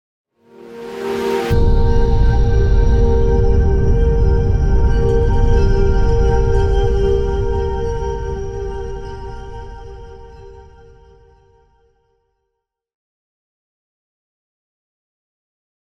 moonbeam-intro_no_pulse-v1-005.ogg